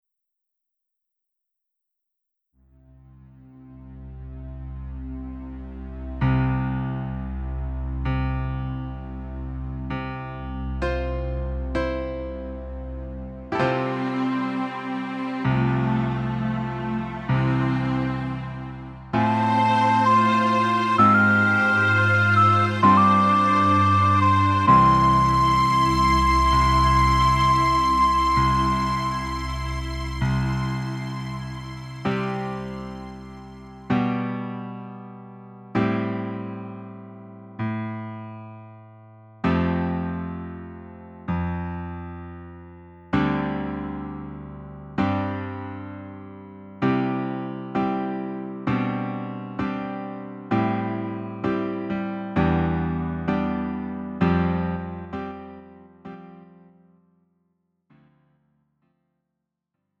음정 원키 4:31
장르 가요 구분 Lite MR
Lite MR은 저렴한 가격에 간단한 연습이나 취미용으로 활용할 수 있는 가벼운 반주입니다.